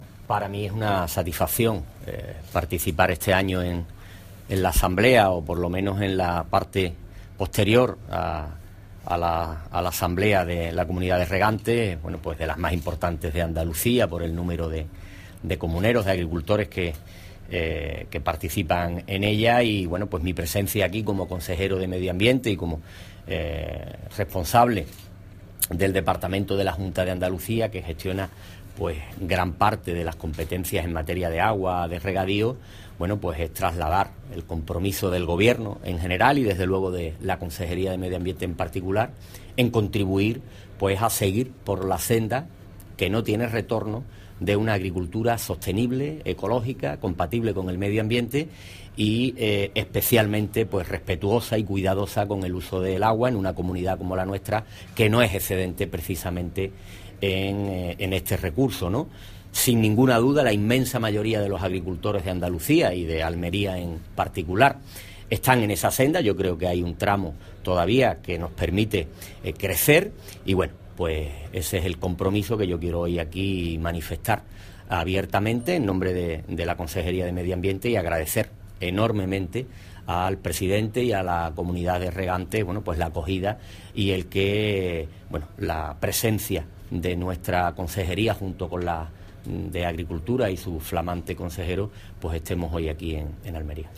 Declaraciones de José Fiscal sobre la asamblea de la Comunidad de Usuarios de Aguas de Níjar